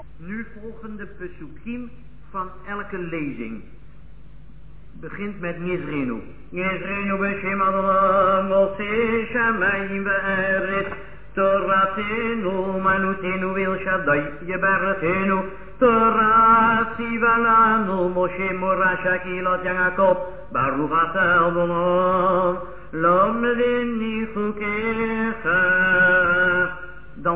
This structure is used during a private Limud (Lezing) and on 15 Shevat.
Introduction Torah, recited